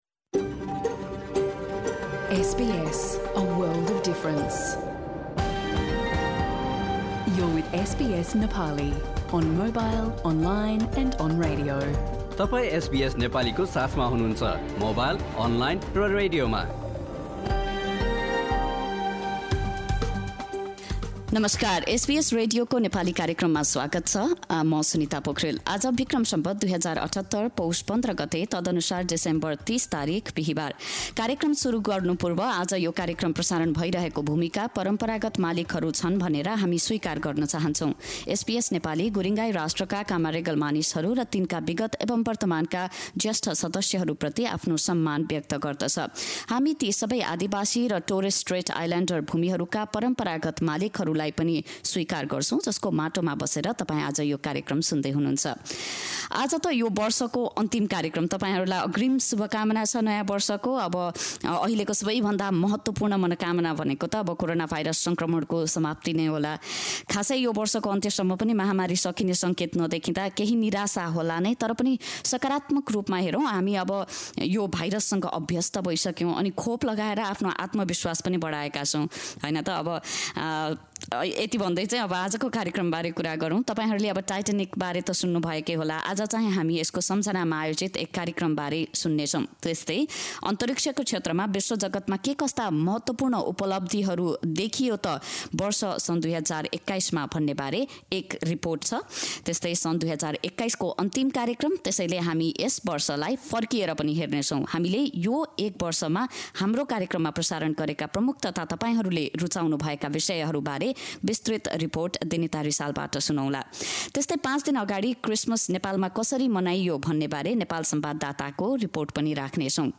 एसबीएस नेपाली रेडियो कार्यक्रम: बिहीबार ३० डिसेम्बर २०२१